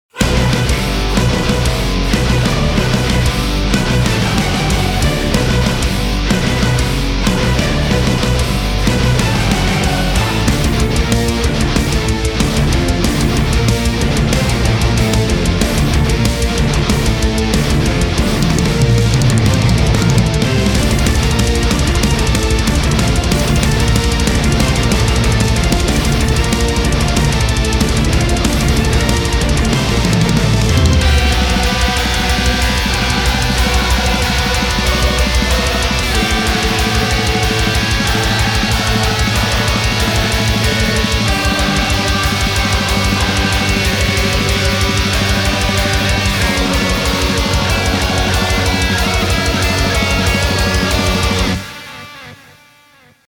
Wer weiss dies schon.^^ Natürlich im kompletten Demo / Recording Mix (bzw. nicht Mix). your_browser_is_not_able_to_play_this_audio